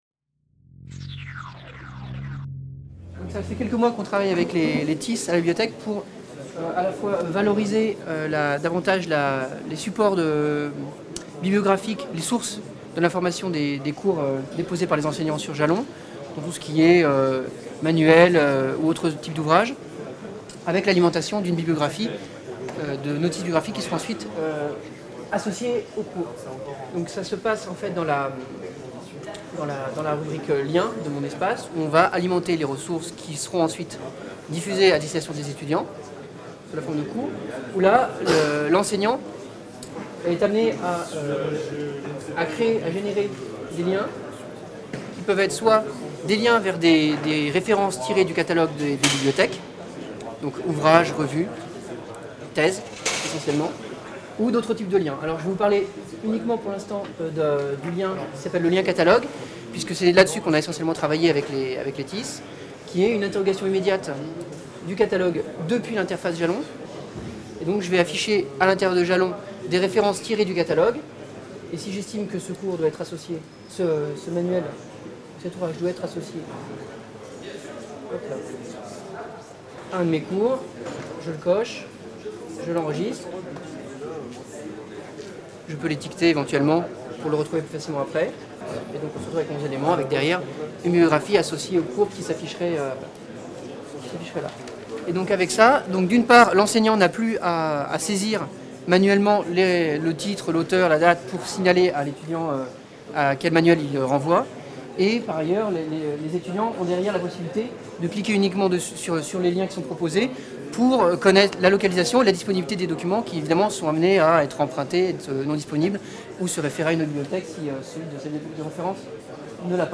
Atelier Journée TICE 2012 : Bibliographies et Cours en ligne | Canal U